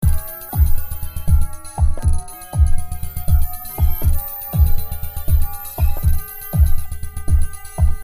FX律动120